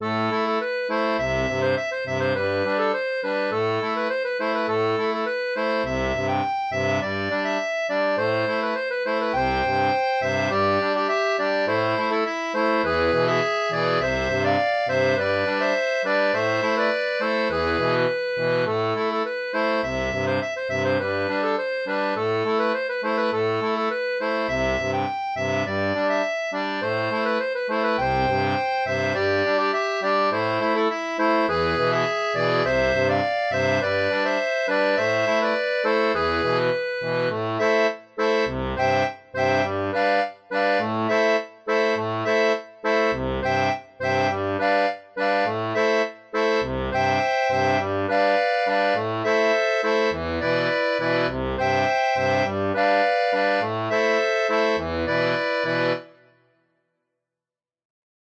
Chanson française